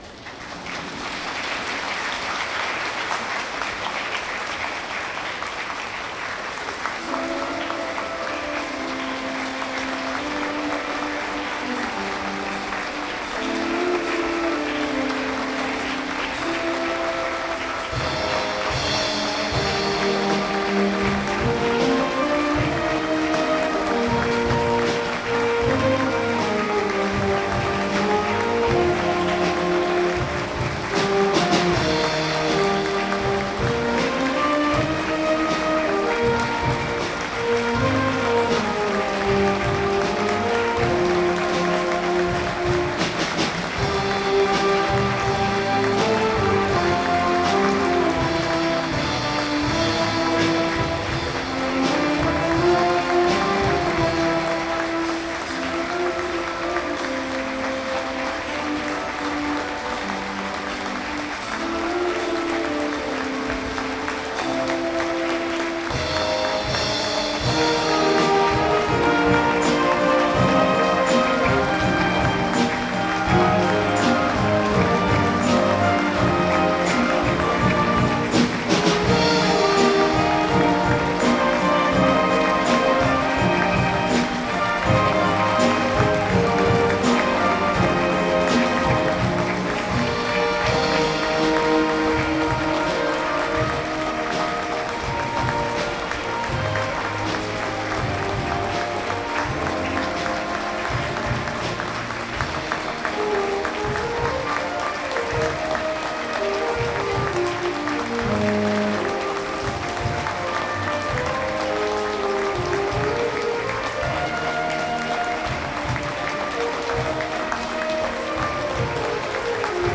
本校第61回卒業式での演奏です。
この曲にのって入場しました。